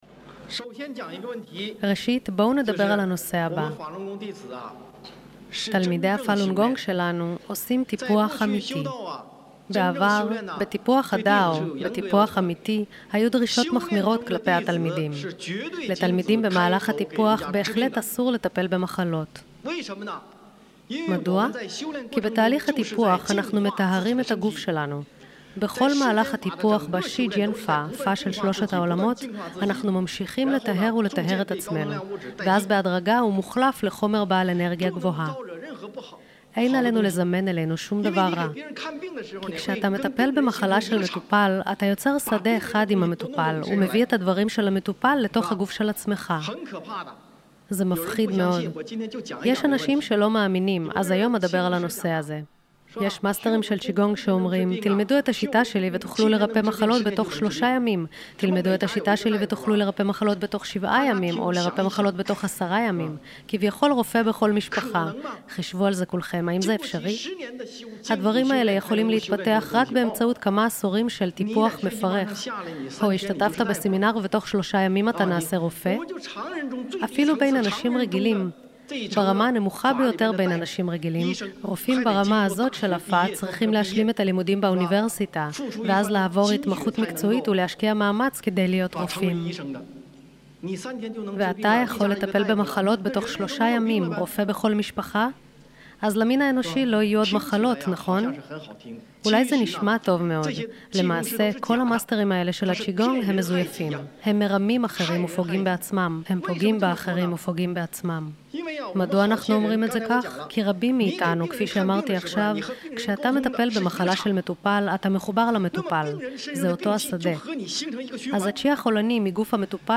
הרצאה 1